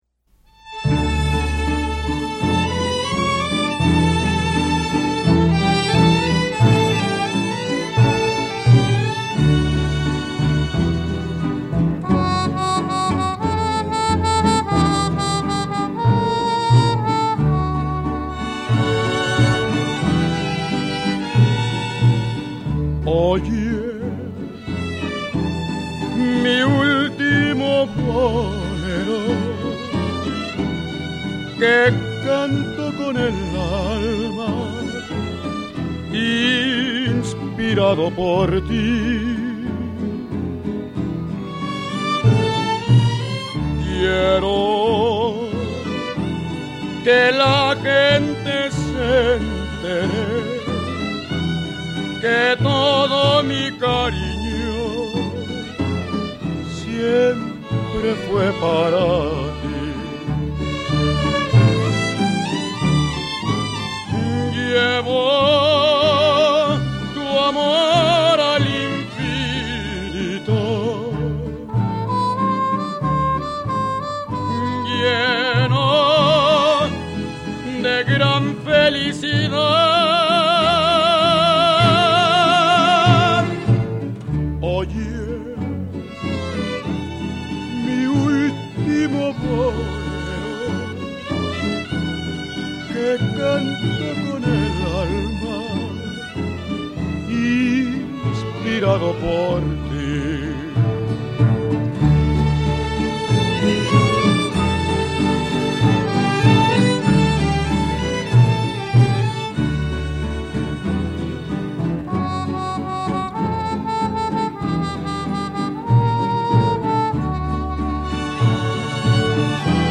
el bolero ranchero.